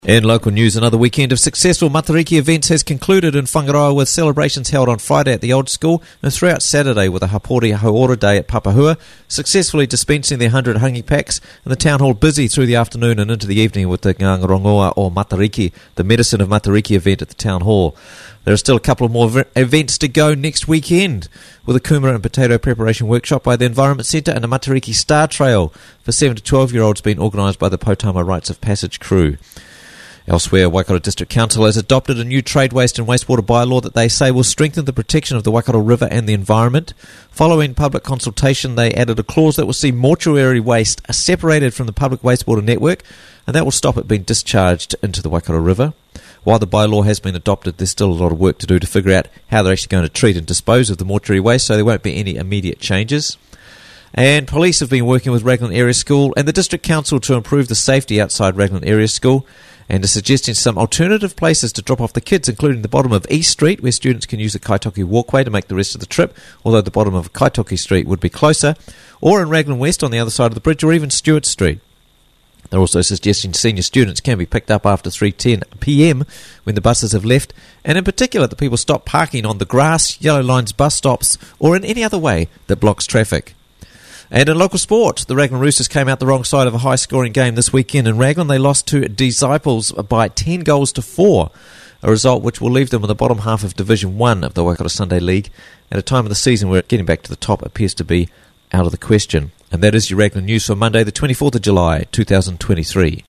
Raglan News Bulletin